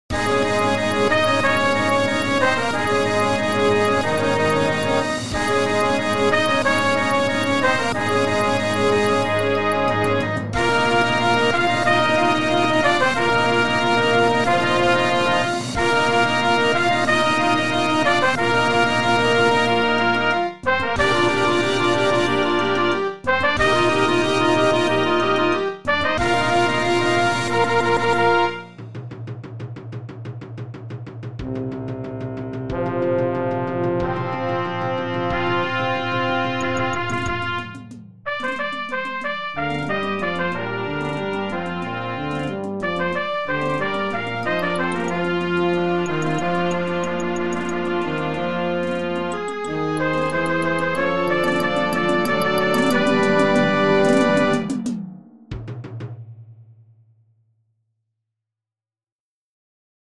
pour les Harmonies
pièce de Concert